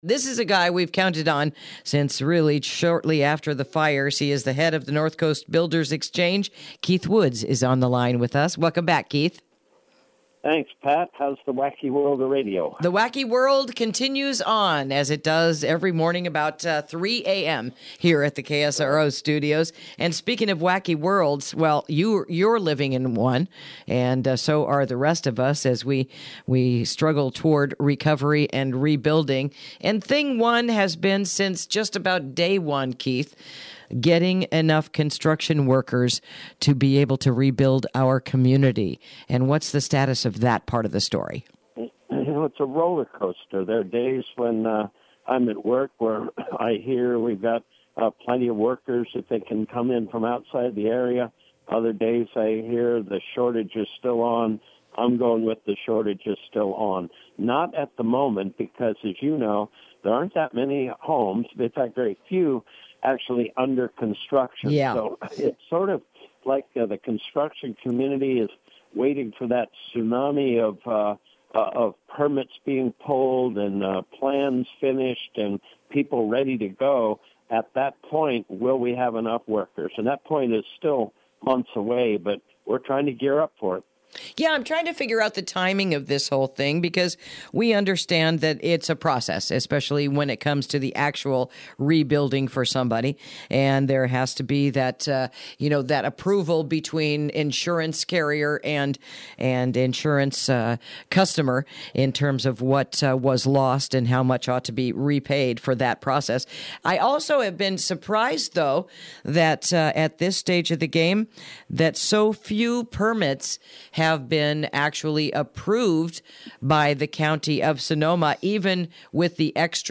Interview: Addressing the Issues With Rebuilding Five Months After the Fires